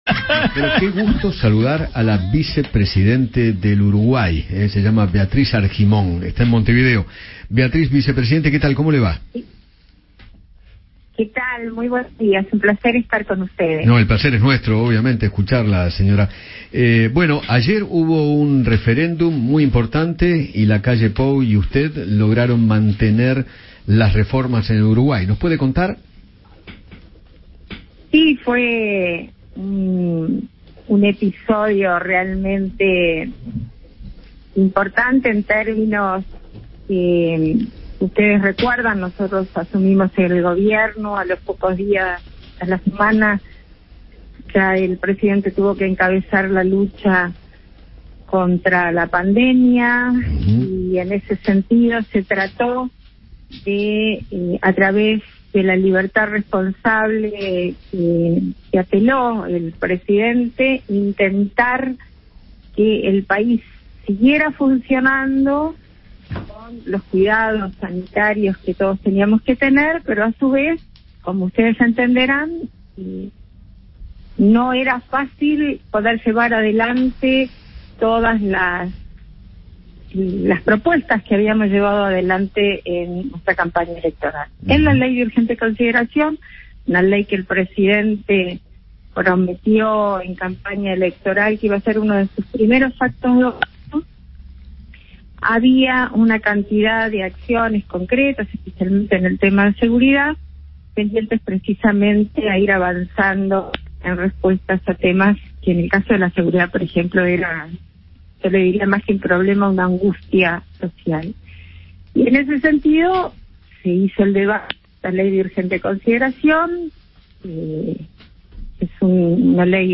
Beatriz Argimón, vicepresidenta de Uruguay, conversó con Eduardo Feinmann sobre el triunfo de Luis Lacalle Pou en el Referéndum de aquel país y recalcó el apoyo de partidos opositores.